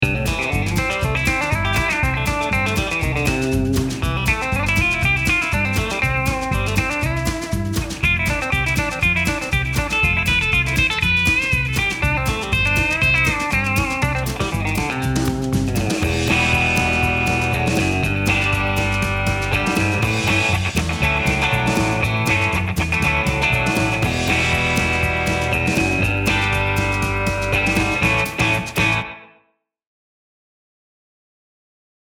This Vintage Tele lead pickup uses alnico 2 rod magnets to provide a softer treble attack for players who want Telecaster tone without excess bite.
APTL-1_CLEAN_BAND_SM